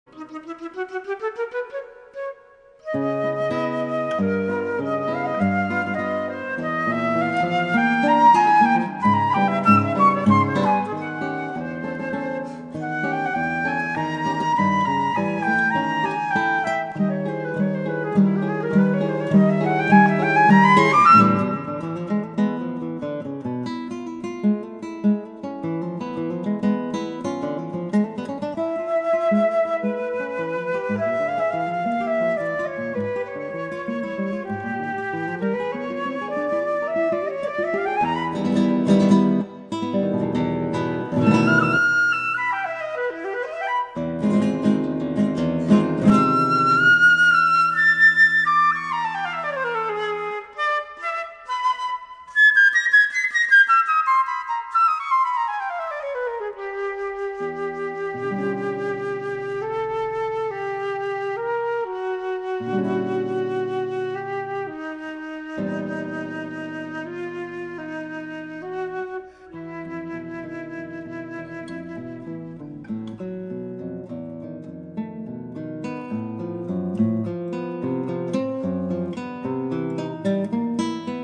per Flauto e Chitarra